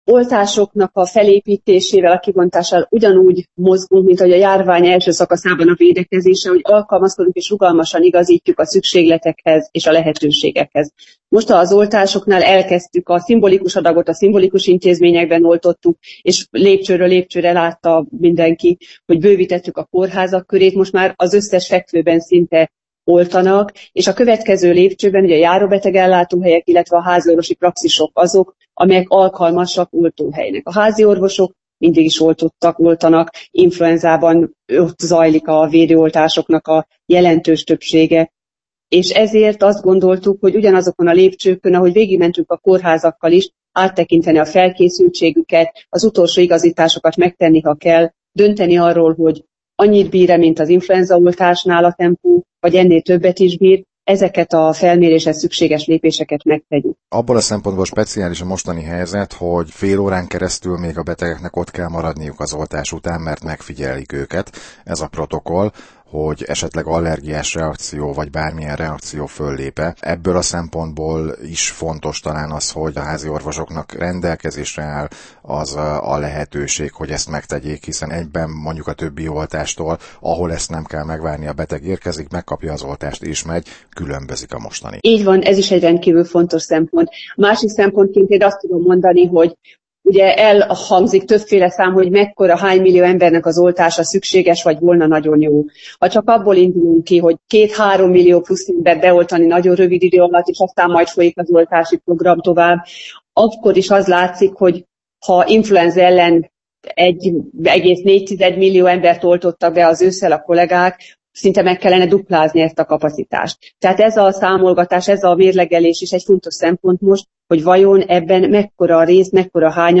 Horváth Ildikó egészségügyért felelős államtitkár az oltópontokról